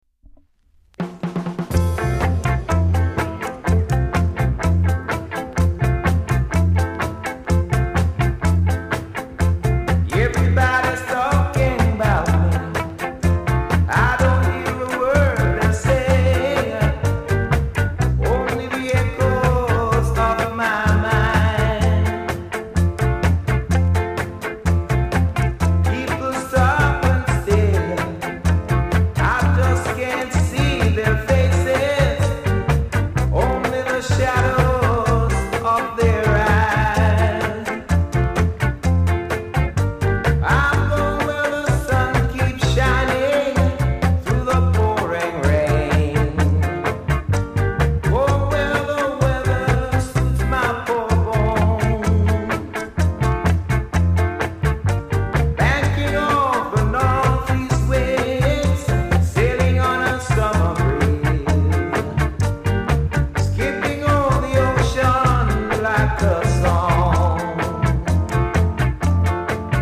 ※若干音が濁って聴こえるような気がします。ほか小さなチリノイズが少しあります。
NICE COVER!!